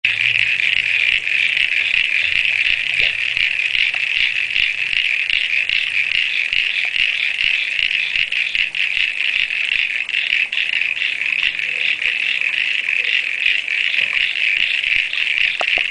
We have at least three species, the first frog started croaking within a week of the pond being filled :).
Here is an audio clip from our pond in the middle of winter.